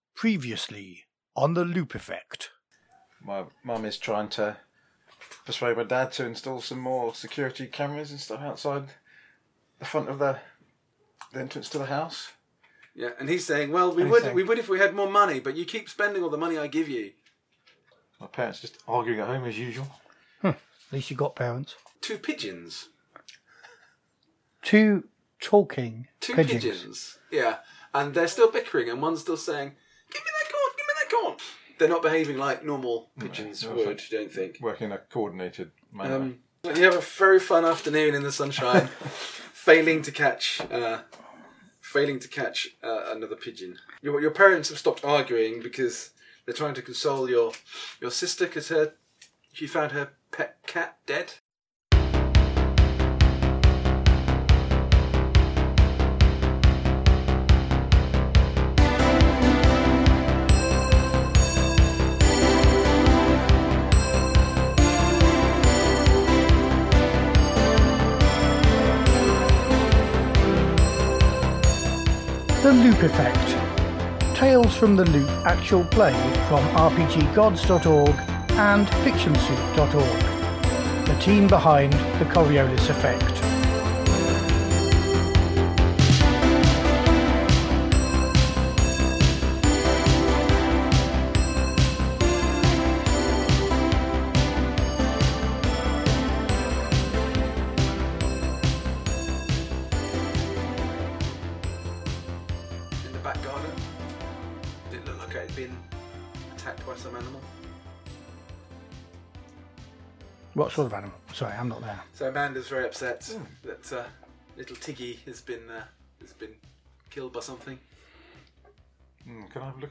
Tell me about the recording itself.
The second part of our Tales from the Loop Actual Play. Tell us what you think of the sound – this is the first recording on our new multi-mic set-up. (I’m often a bit louder than the others as I made the schoolboy error of placing my mic directly between me and the GM… doh!)